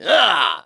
One of Ludwig's voice clips in New Super Mario Bros. Wii